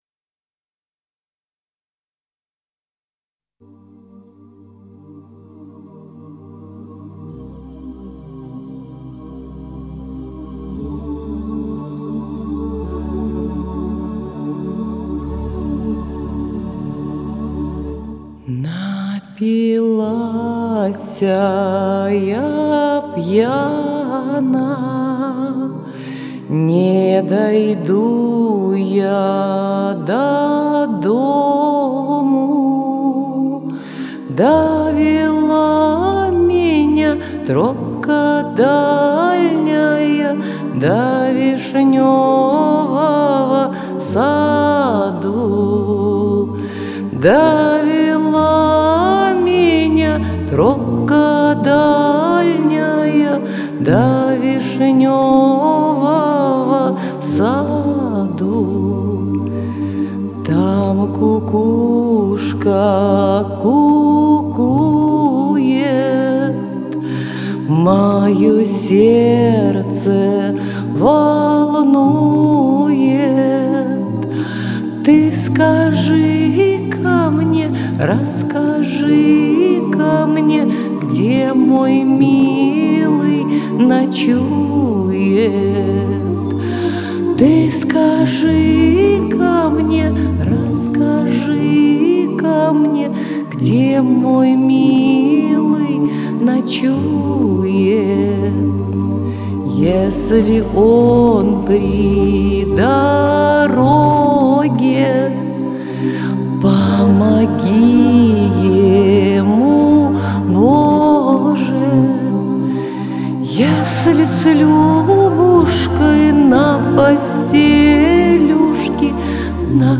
Романсы, городской фольклор и народные песни.